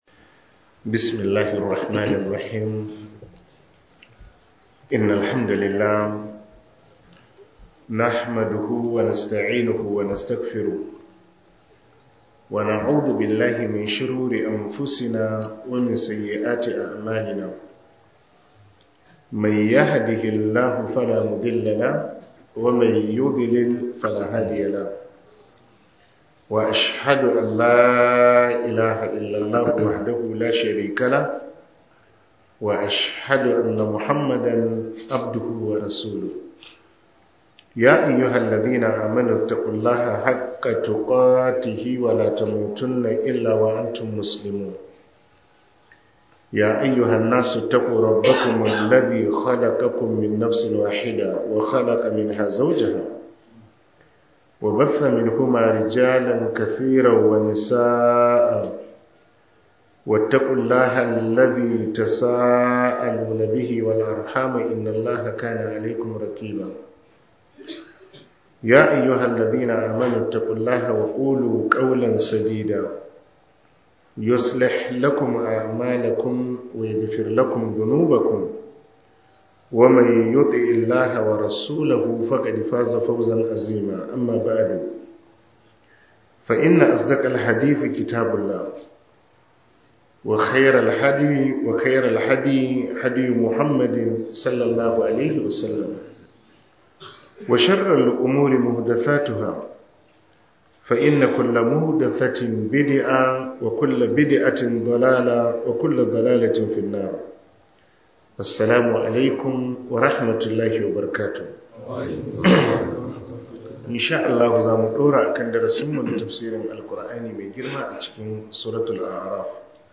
13 Ramadan Tafsir